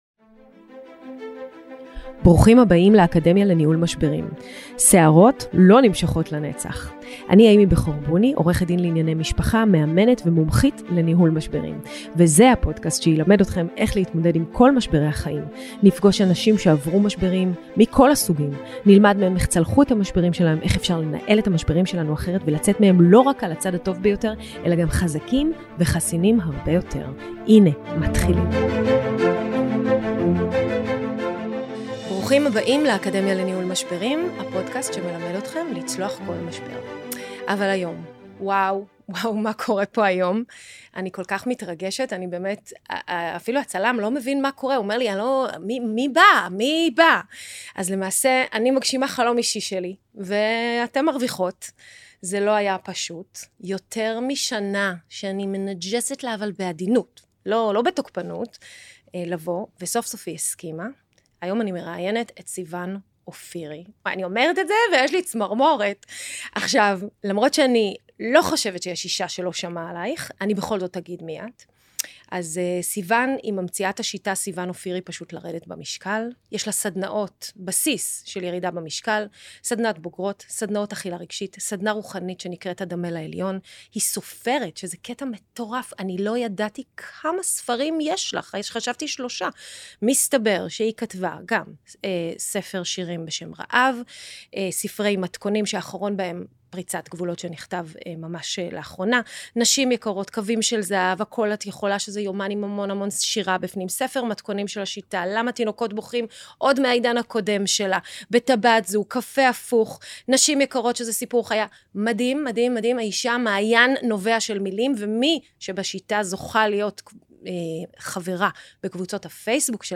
ראיון חובה!